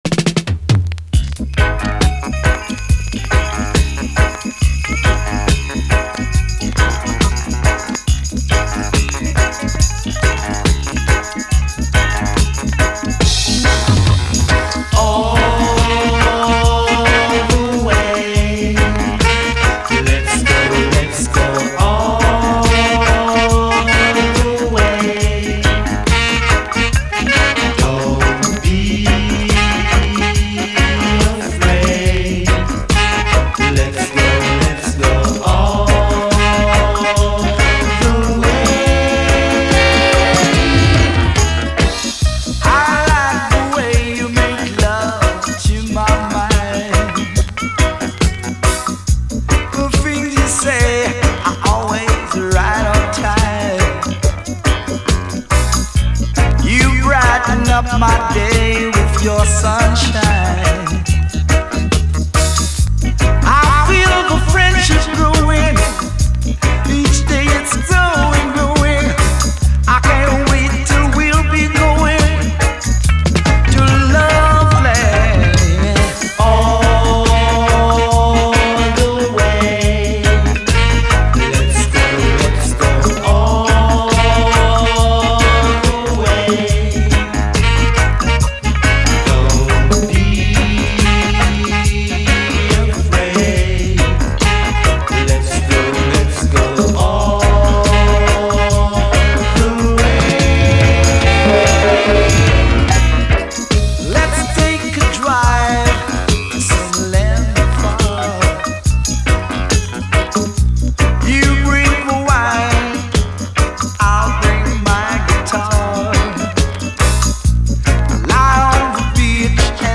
REGGAE
盤面細かいスレかなり目立ちプレイVG+〜VGくらい。